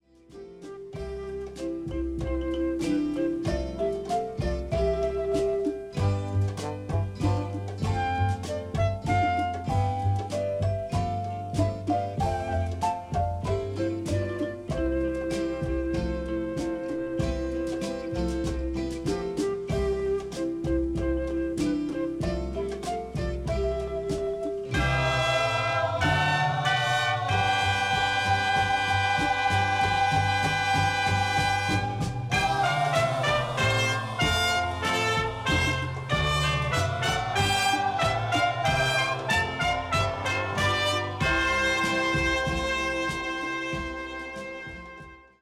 ハッピーでスウィートなウィンター・ソングが詰まっています。